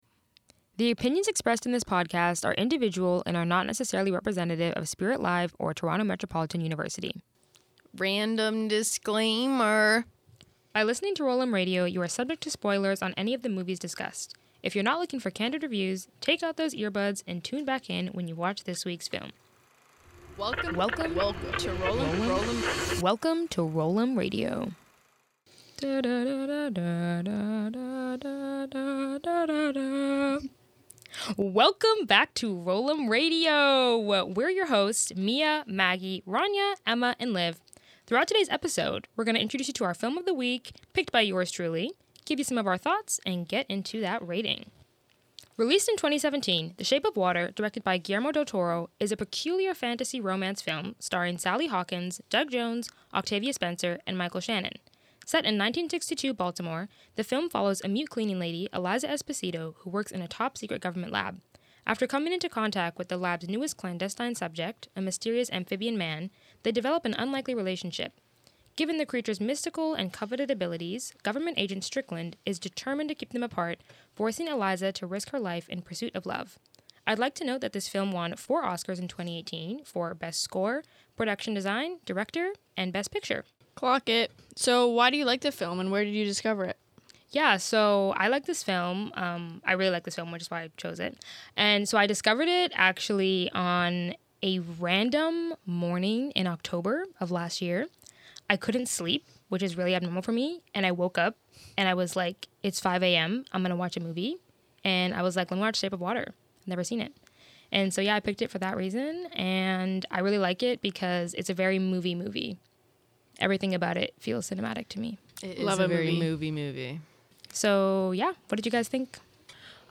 One movie, five critics, and one final verdict.